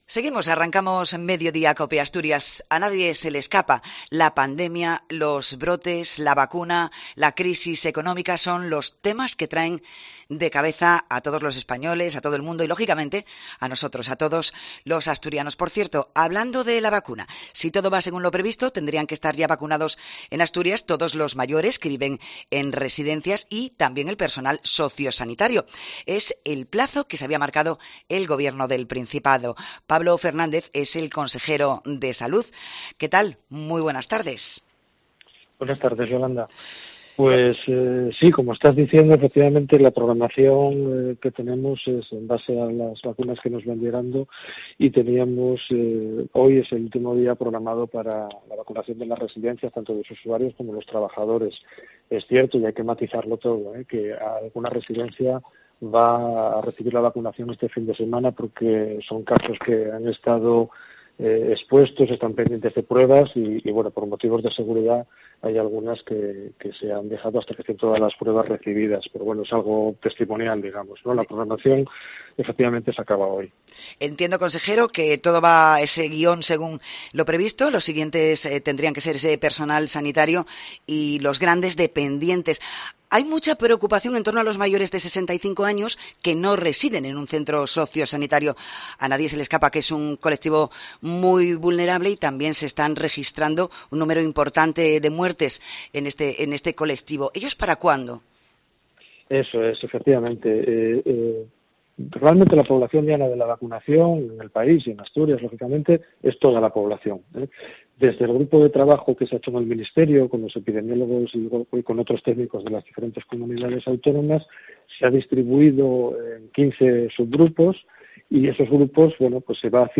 Entrevista a Pablo Fernández en el Mediodía COPE Asturias